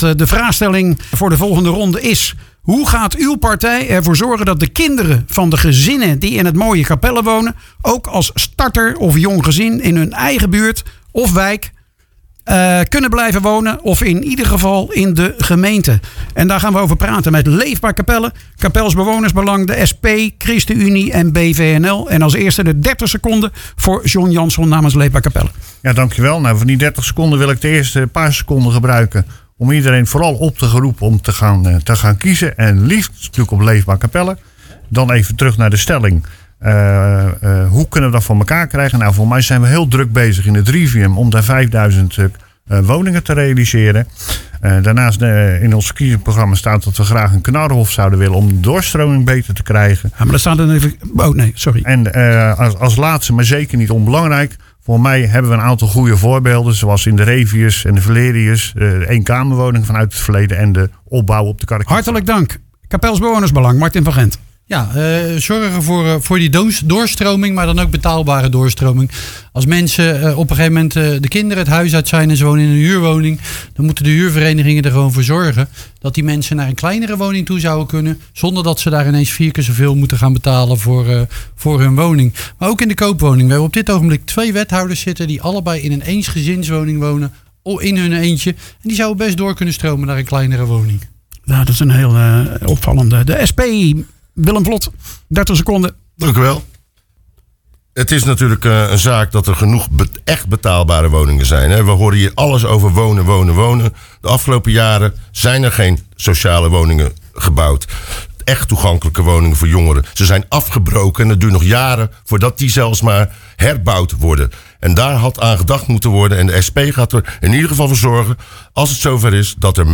Op zaterdag 12 maart was in de studio van Radio Capelle het laatste verkiezingsdebat voordat de stembureaus opengaan.
Je hoort Leefbaar Capelle, Capels Bewoners Belang, SP, ChristenUnie en BVNL.